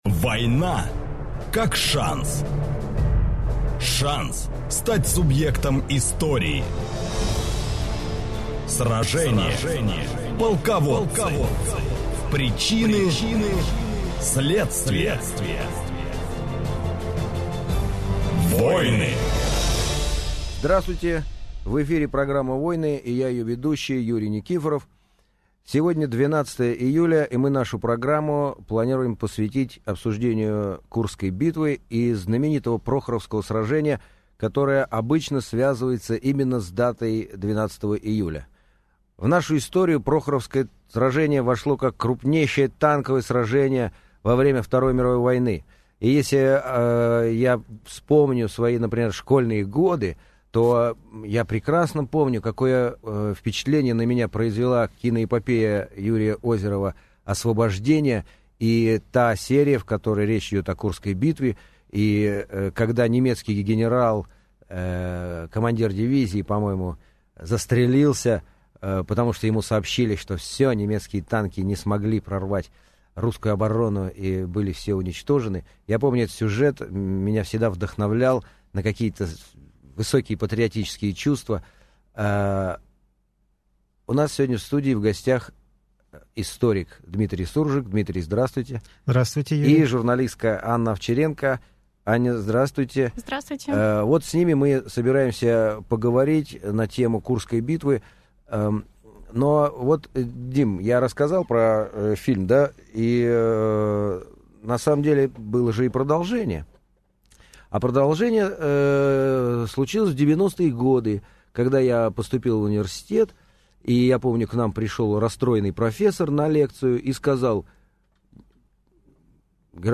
Аудиокнига Прохоровское сражение | Библиотека аудиокниг